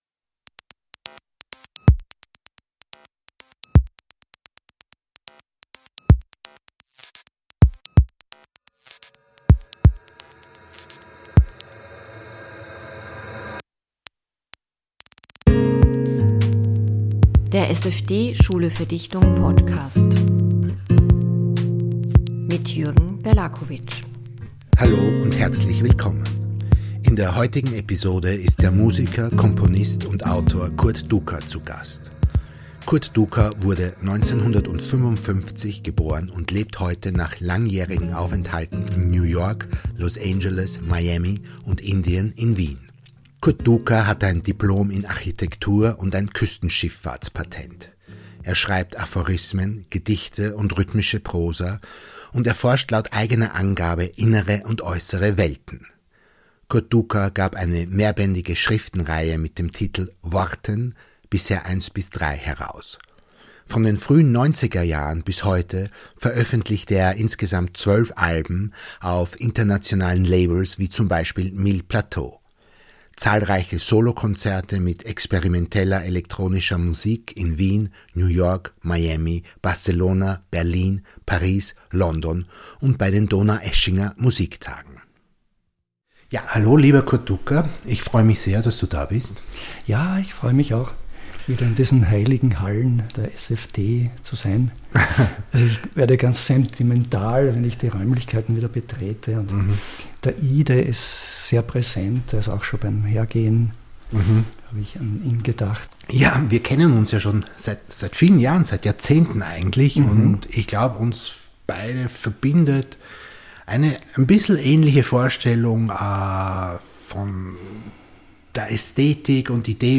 der autor und musiker im gespräch